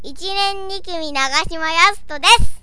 当時(1995年。30年前だぁ!)のMacの信号処理はこのあたりが限界で、この声は神戸山手女子短期大学音楽科の生徒たちに「カワイイ」と受けていたのだが、今ではその大学そのものが消滅してしまったのだから、時間というのは恐ろしい。